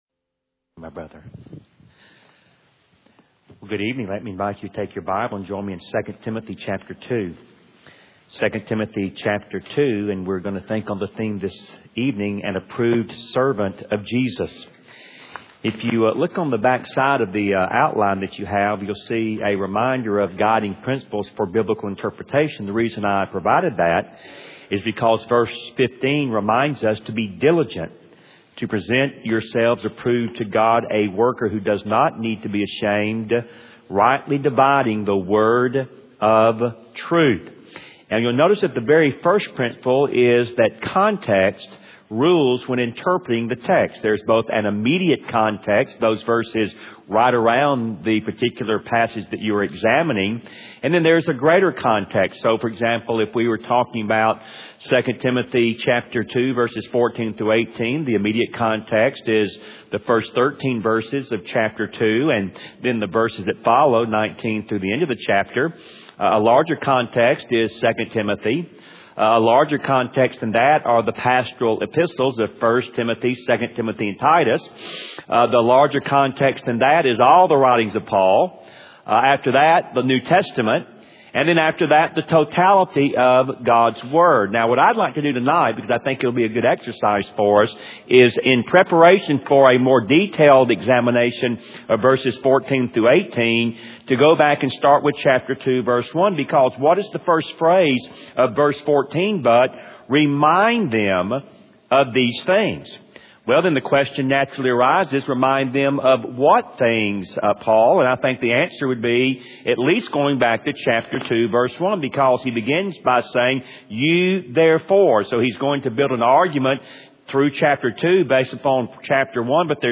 AÂ sermonÂ delivered